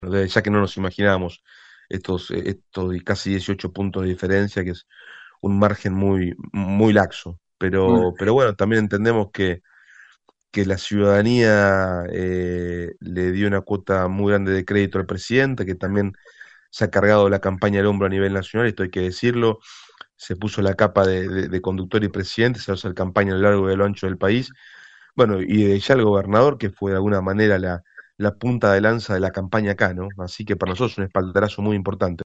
“El mayor porcentaje de votantes en la provincia, tuvo que ver que el gobernador se haya puesto al frente de esta campaña, movilizando a la gente que vaya a votar”, aseguró el ministro de Gobierno, Manuel Troncoso a Radio RD 99.1